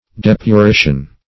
depurition - definition of depurition - synonyms, pronunciation, spelling from Free Dictionary
Depurition \Dep`u*ri"tion\, n.